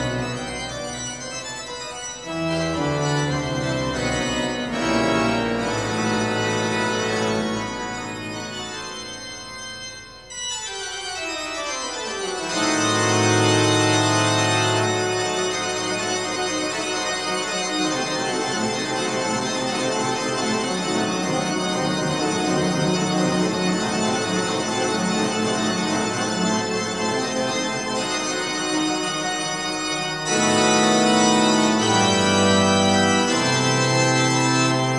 # Solo Instrumental